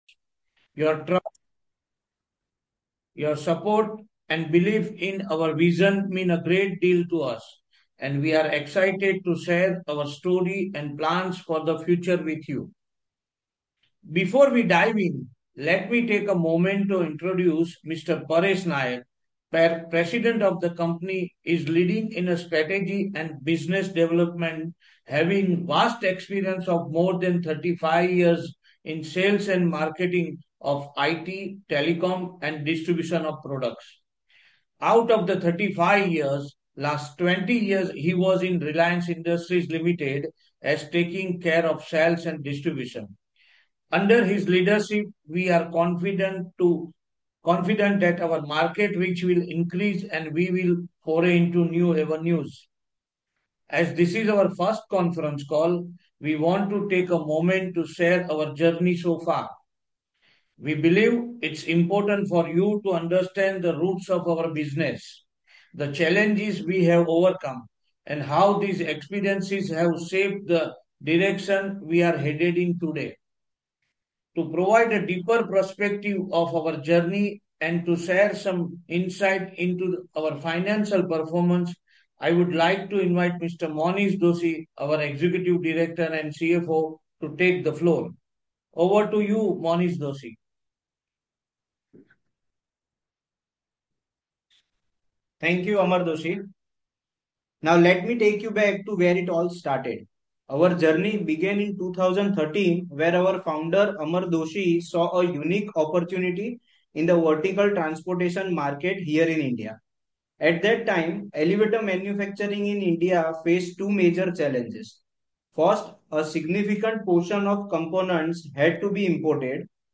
Concalls
Concall_Recording.mp3